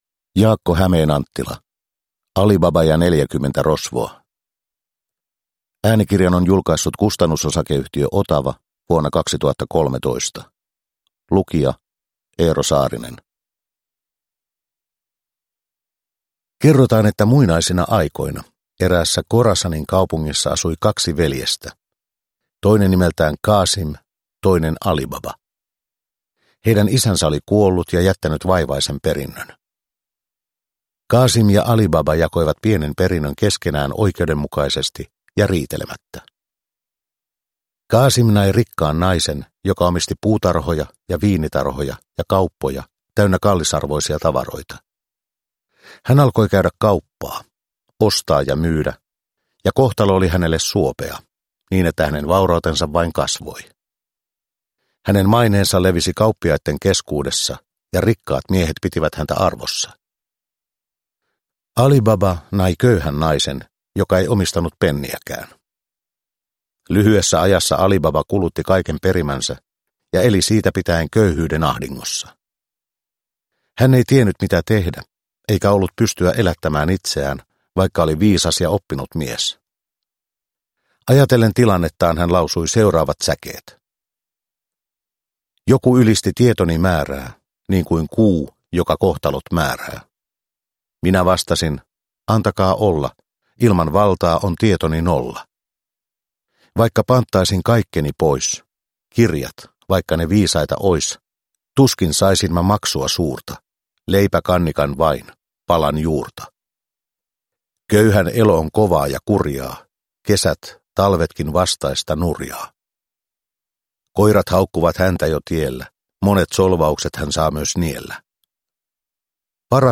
Ali Baba ja neljäkymmentä rosvoa – Ljudbok – Laddas ner